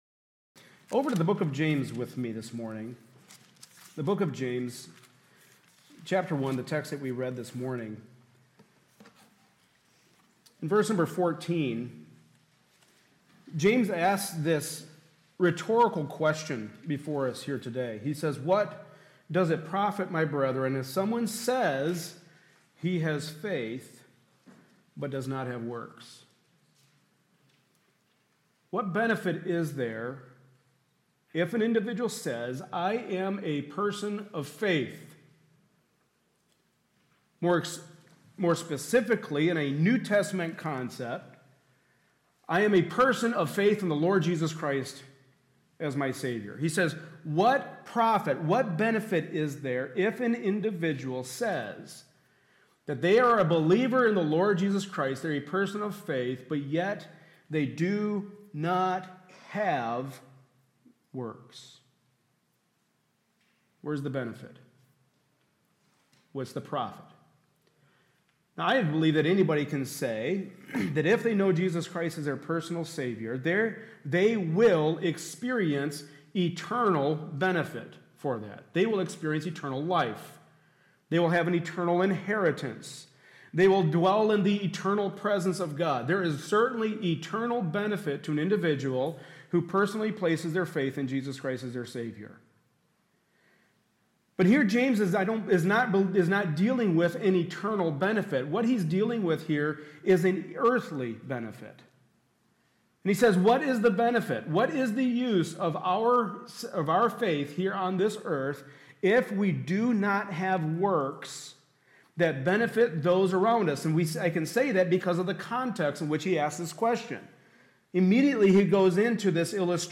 Service Type: Sunday Afternoon Service